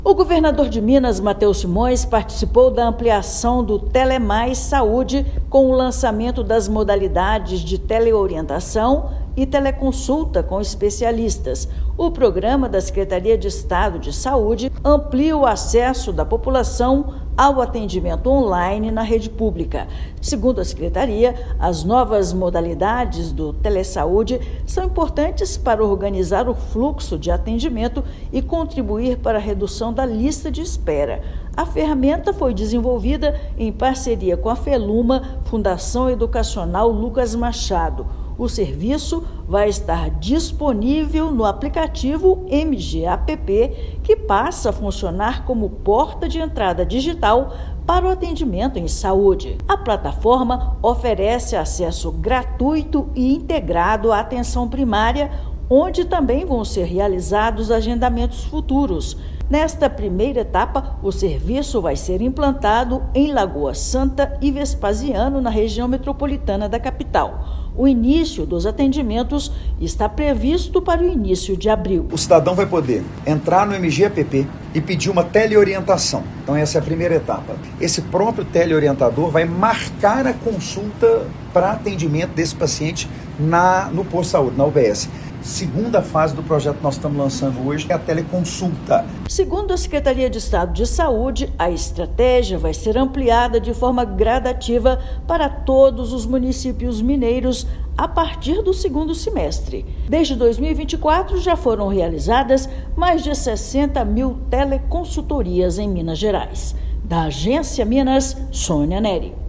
População de Lagoa Santa e Vespasiano, com acesso ao MG App, terá na palma da mão encaminhamentos mais rápidos e assertivos na rede de saúde. Ouça matéria de rádio.